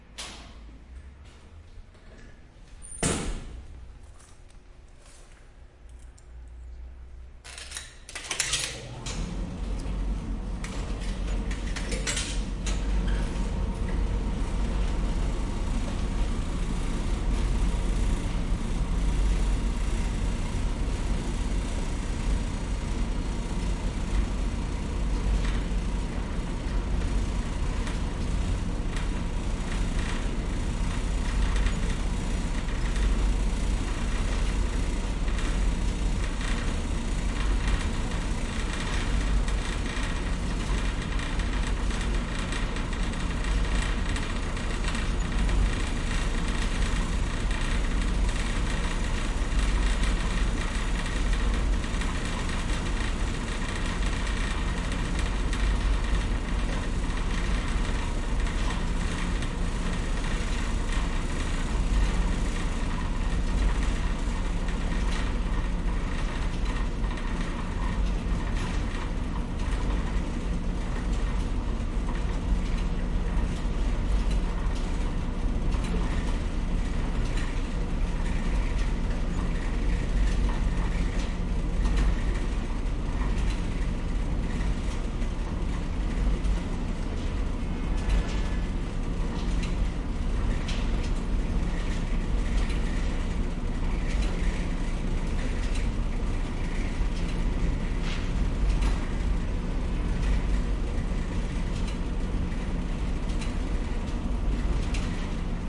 自助洗衣店 " 自助洗衣店烘干机打开关闭开始振动发出响声
描述：洗衣店烘干机打开关闭开始振动拨浪鼓.flac
标签： 开放 洗衣店 关闭 烘干机 启动 震动 撞击
声道立体声